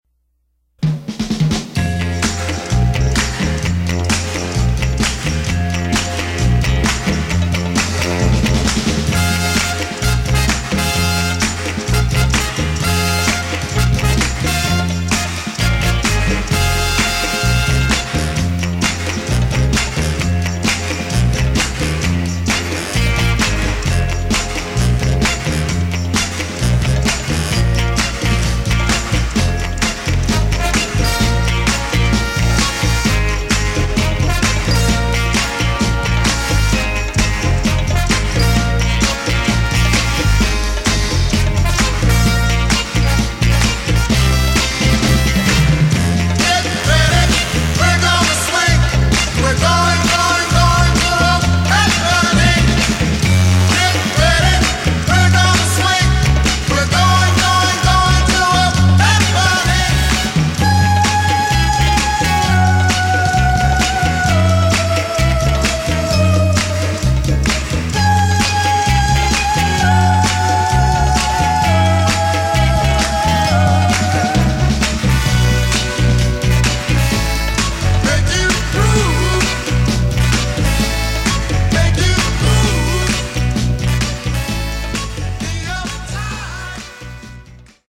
Detroit music
powerful vocal